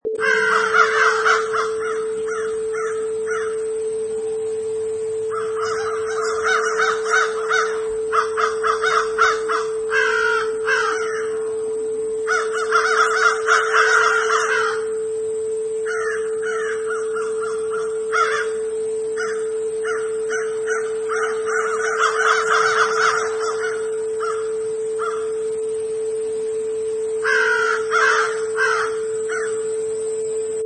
Sound: Crows
Multiple crows far and wide calling and cawing
Product Info: 48k 24bit Stereo
Category: Animals / Birds
Try preview above (pink tone added for copyright).
Crows.mp3